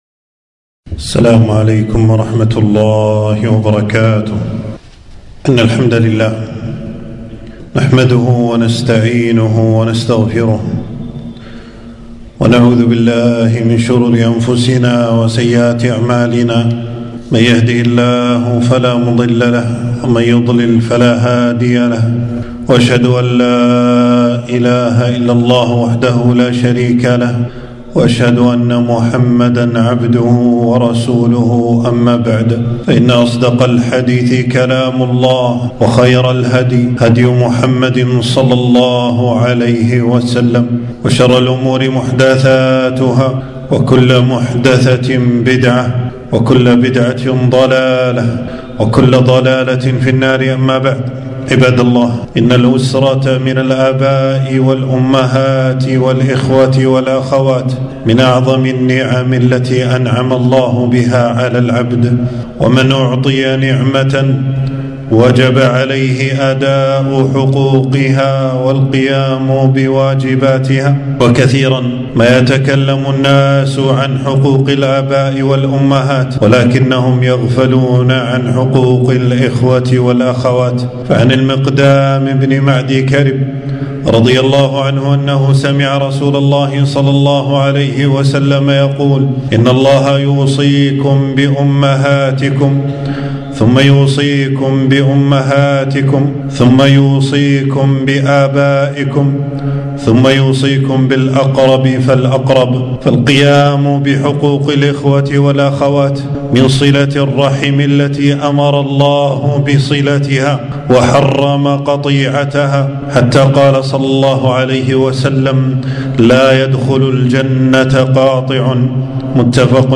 خطبة - إياكم وقطيعة الإخوة والأخوات